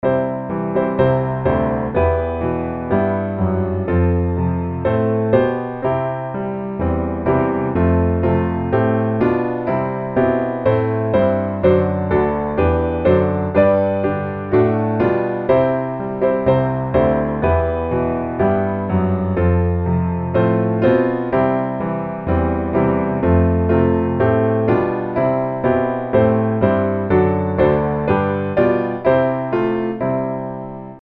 调号： C大调 节奏： 4/4